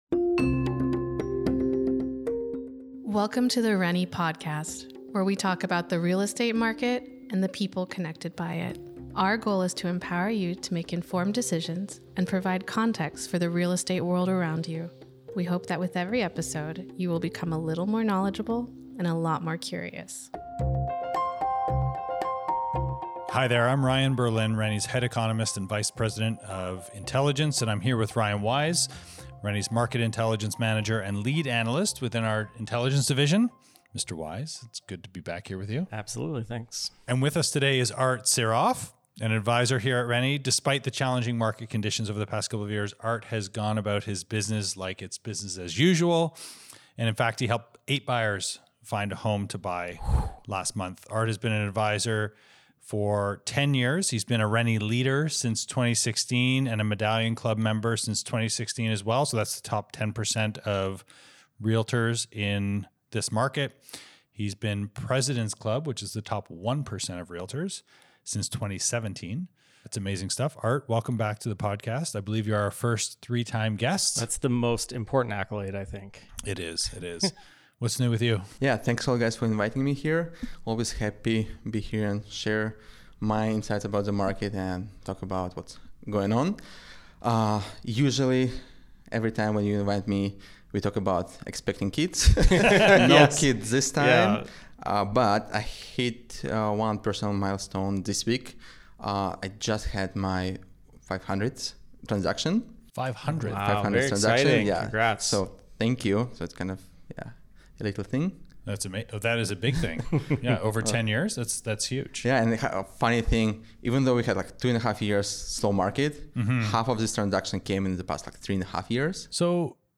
Listen now the art of the pre-sale 2024-08-22 • Episode 66 0:00 0 Subscribe  All episodes The rennie podcast is about the real estate market and the people connected by it. Tune in for monthly discussions making sense of the latest market data.